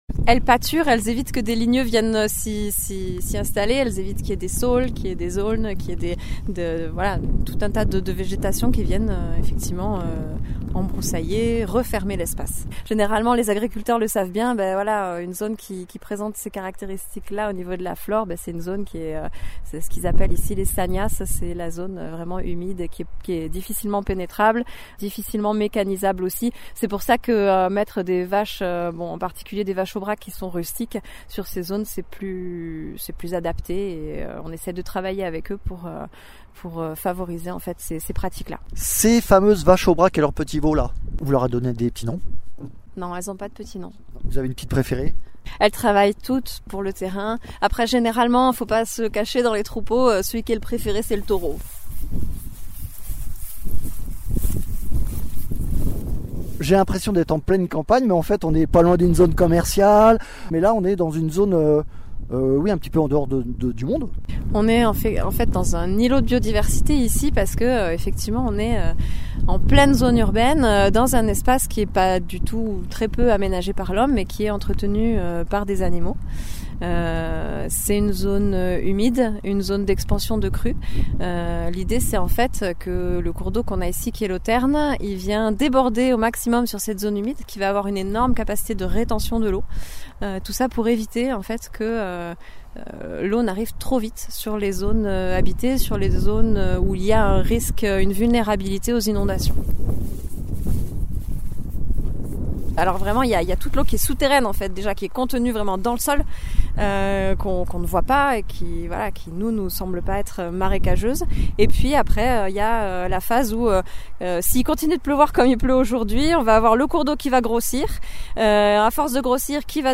Reportage à Nostre seigne à Onet le Chateau, une zone humide aménagée par le syndicat mixte du bassin versant Aveyron Amont.
Interviews